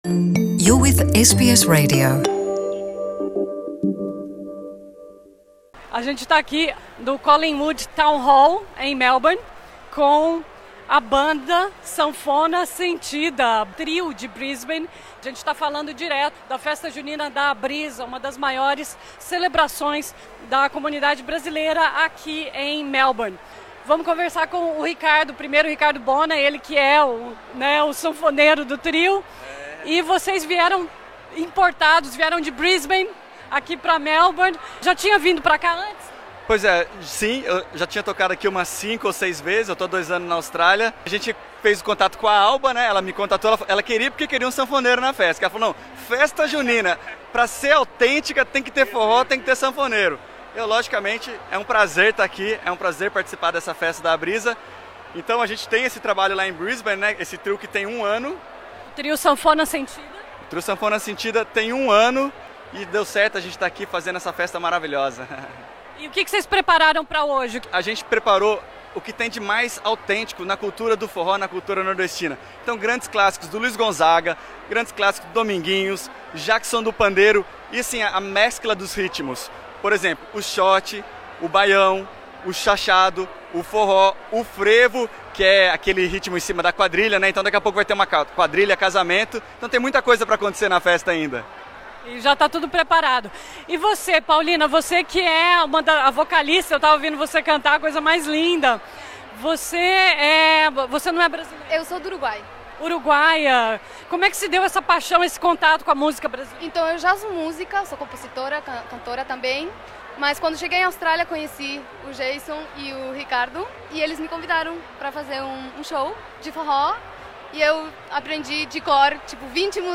Ouça a cobertura completa do evento e entrevistas com expositores, participantes